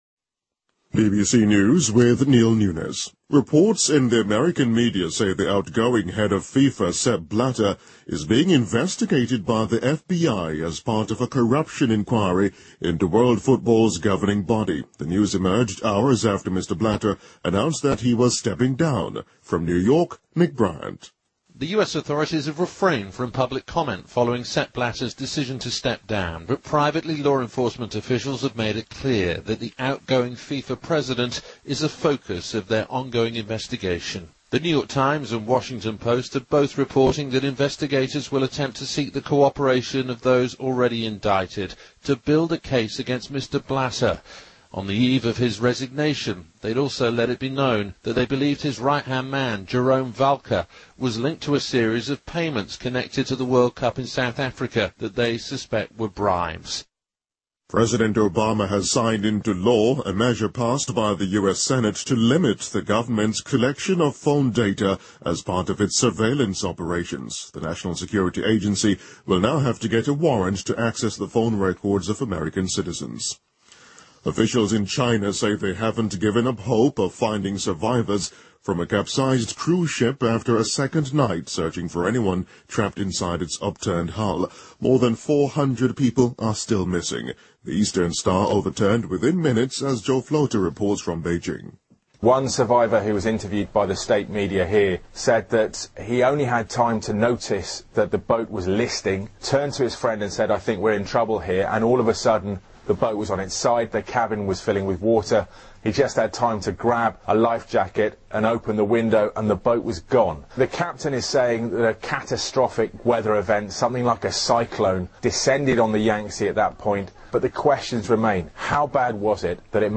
BBC news,国际足联主席布拉特将接受联邦调查局的调查